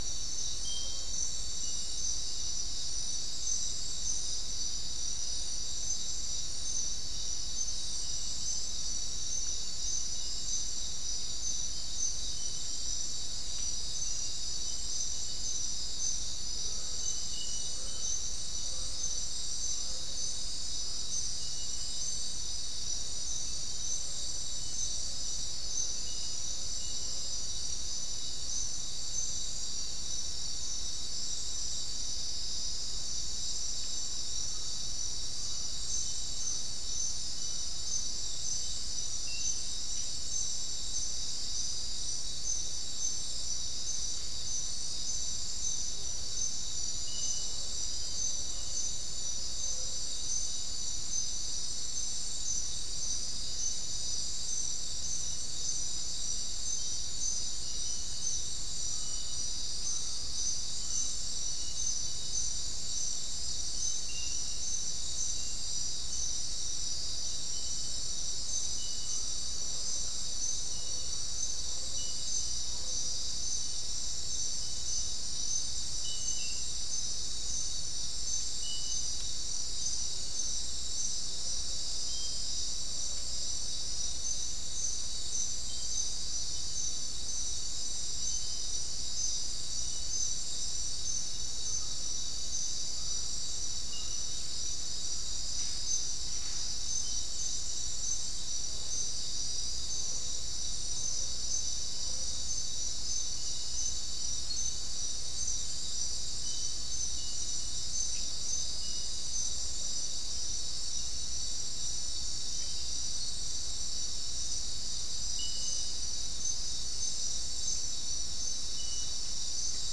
Soundscape
South America: Guyana: Mill Site: 4
Recorder: SM3